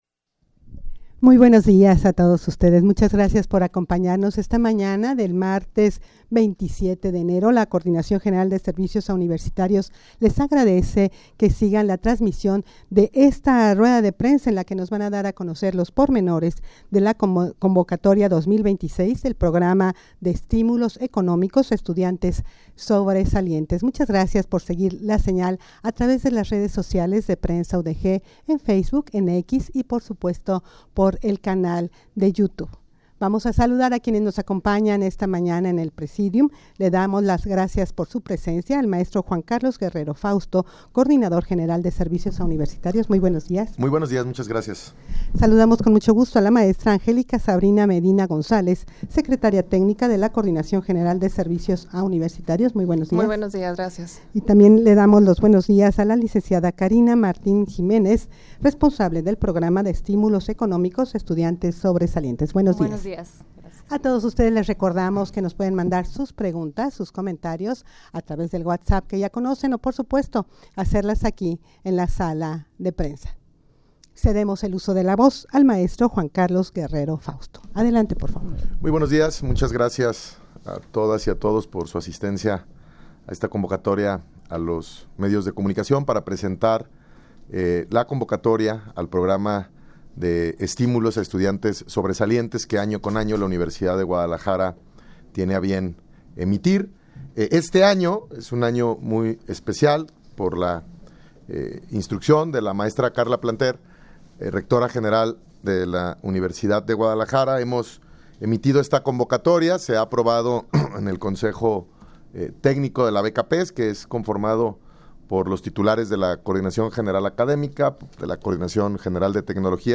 Audio de la Rueda de Prensa
rueda-de-prensa-para-presentar-los-pormenores-de-la-convocatoria-2026-del-peees.mp3